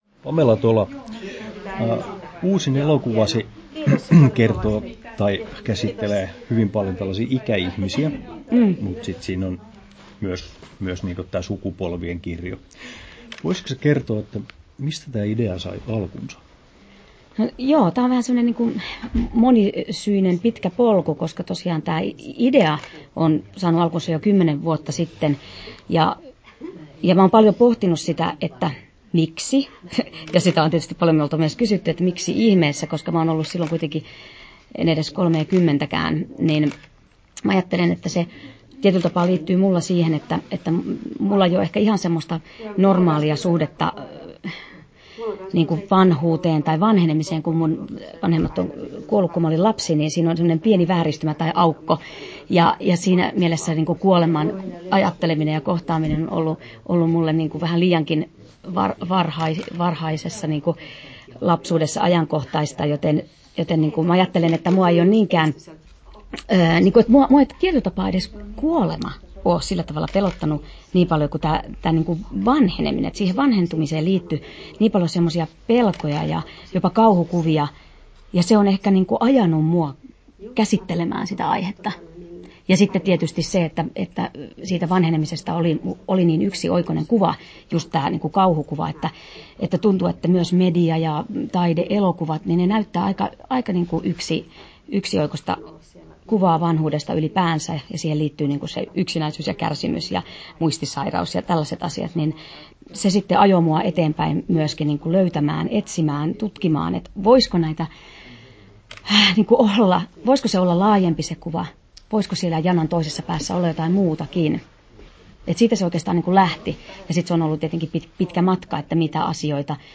Haastattelussa Pamela Tola Kesto: 11'19" Tallennettu: 18.12.2019, Turku Toimittaja